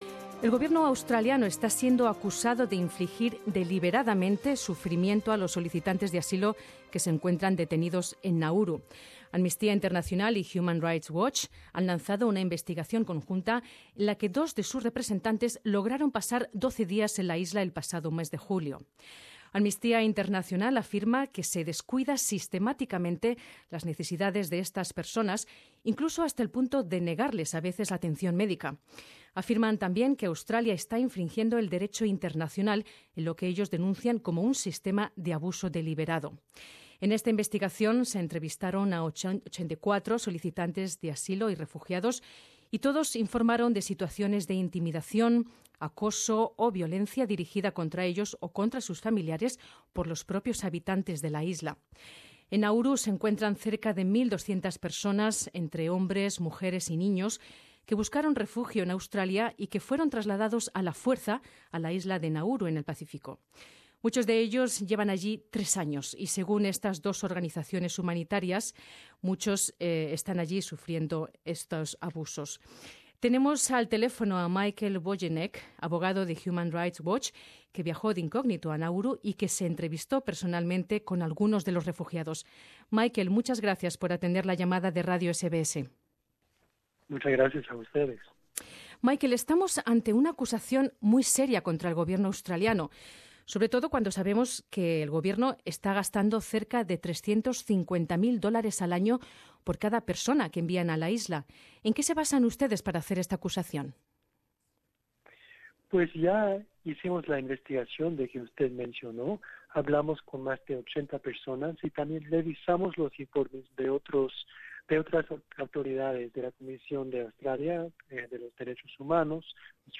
Un abogado de Human Rights Watch que viajó de incógnito a Nauru, nos habla de la situación de los refugiados.